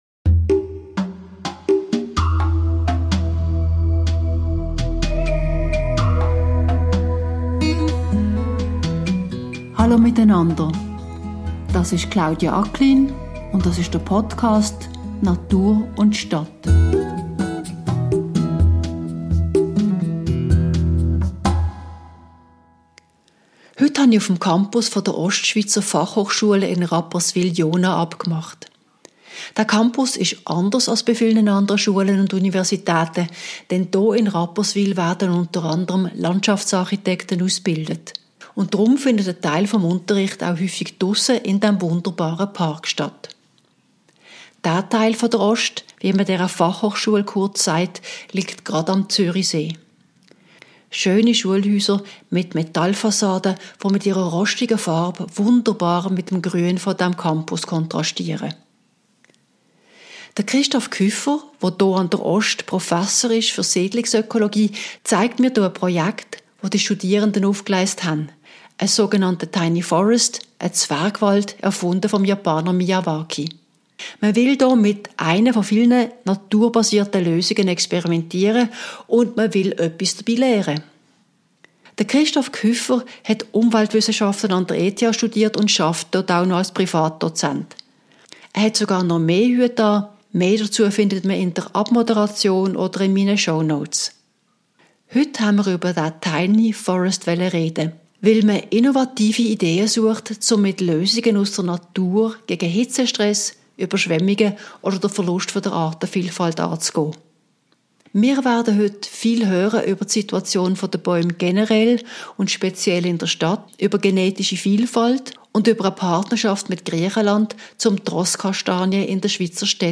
- Ich besuche die Fachhochschule OST in Rapperswil, wo Landschaftsarchitekten ausgebildet werden.